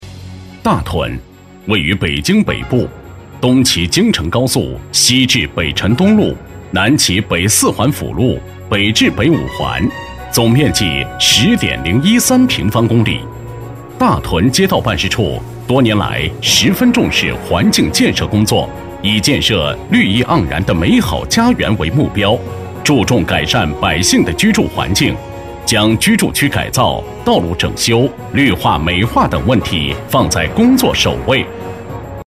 激情力度 规划总结配音
磁性稳重男音，擅长地产、军事解说、纪录片、政府汇报题材。